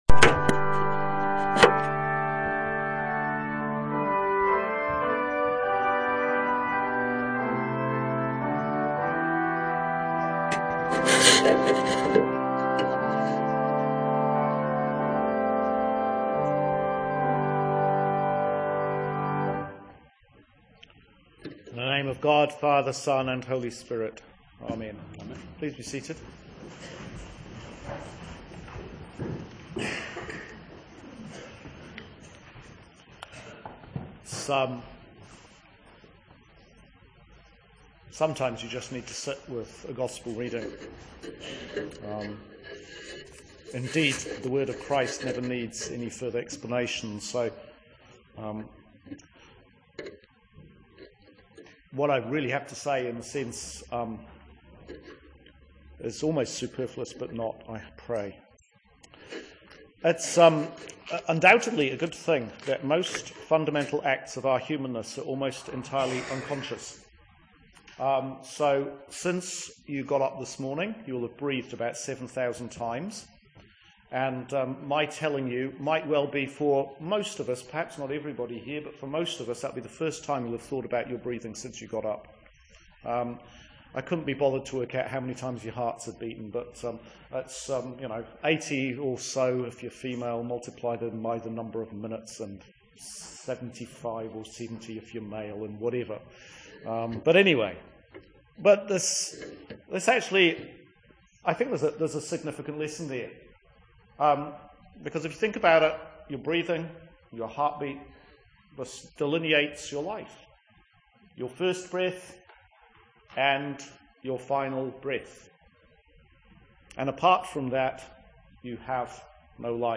Sermon for Christ the King Sunday, 2014. Year A. ‘Breathing’